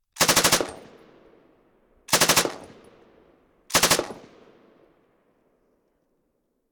Звук пулемета: короткие очереди
Тут вы можете прослушать онлайн и скачать бесплатно аудио запись из категории «Оружие, выстрелы, война».